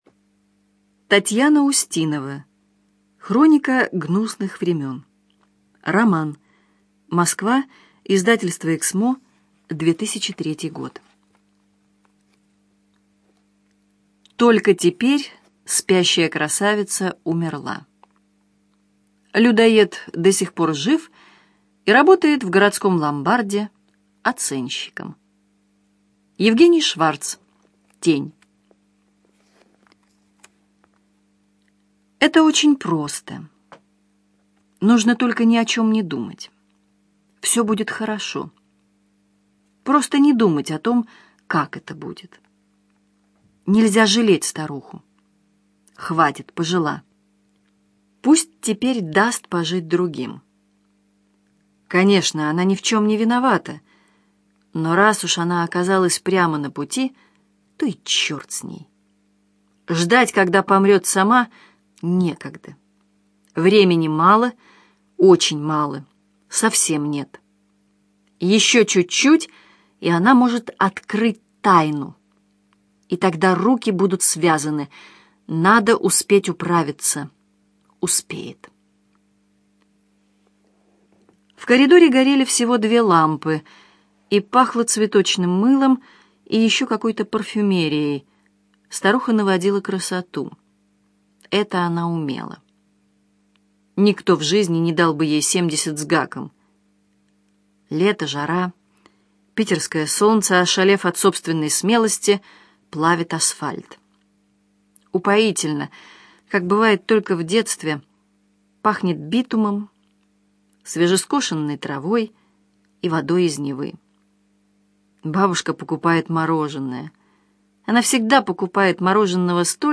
Аудиокнига Хроника гнусных времен - купить, скачать и слушать онлайн | КнигоПоиск